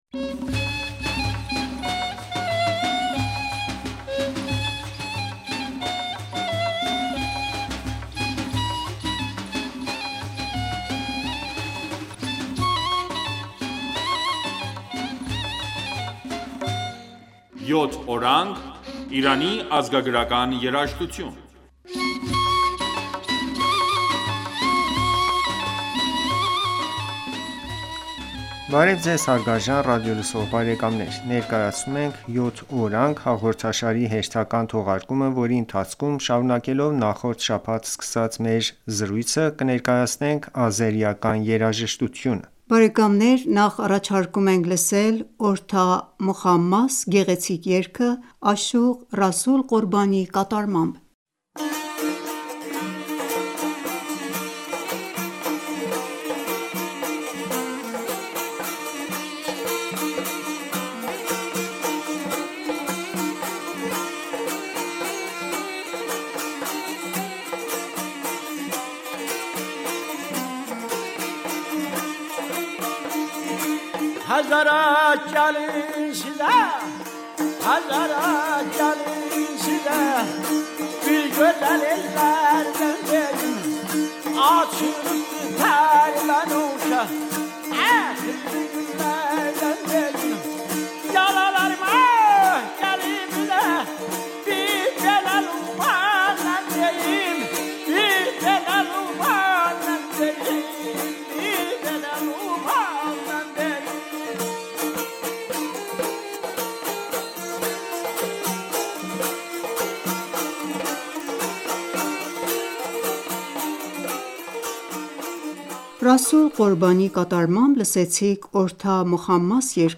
Բարև ձեզ հարգարժան ռադիոլսող բարեկամներ: Ներկայացնում ենք «Յոթ օրանգ» հաղորդաշարի հերթական թողարկումը,որի ընթացքում, շարունակելով նախորդ շաբաթ սկսած մեր զր...